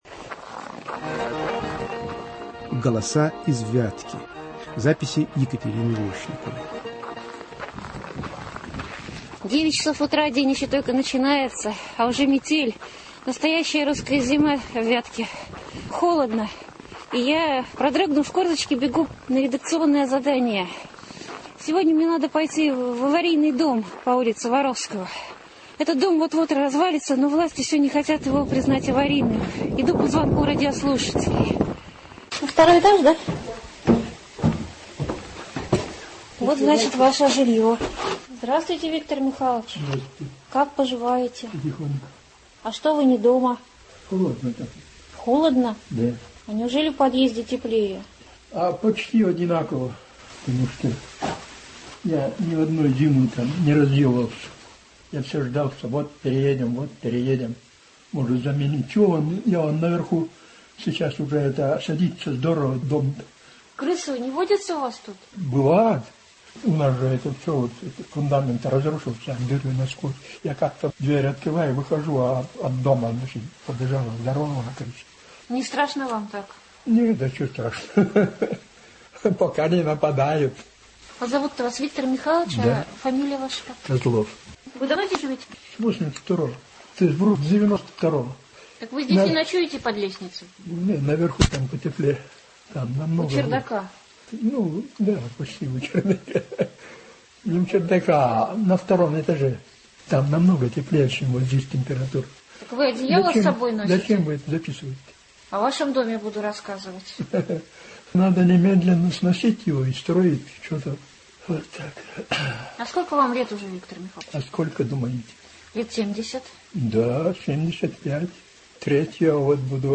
Голоса из Вятки". Жители Вятки рассказывают драматические и комические истории о себе и своём городе.